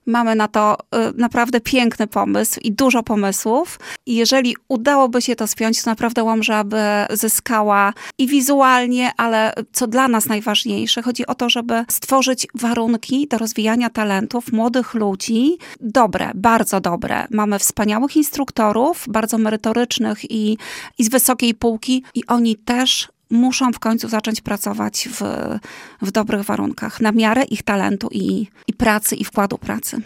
Wicestarosta powiatu Anna Gawrych mówiła na naszej antenie, że ROK jest sercem kultury powiatu, dlatego trzeba tam tworzyć lepsze warunki do tego, aby młodzież mogła rozwijać swoje talenty.